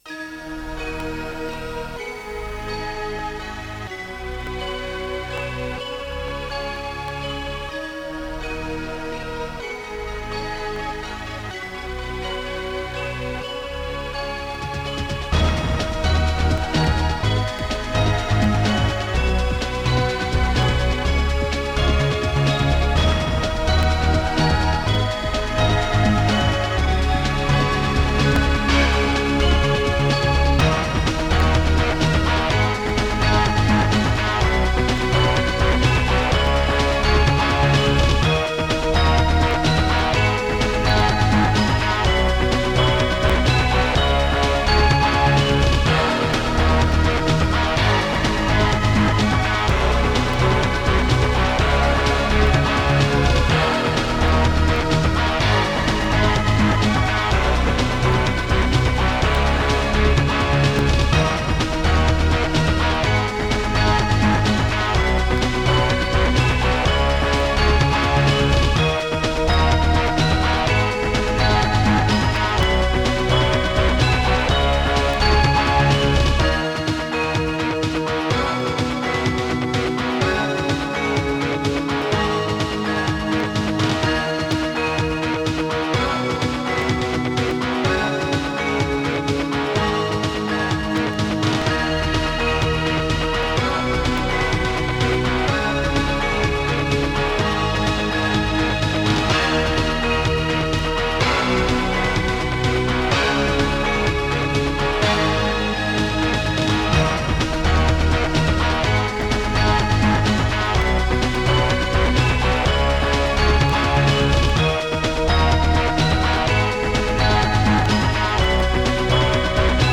Music: tracker 8-channel .s3m
Creative SoundBlaster 2.0 ct1350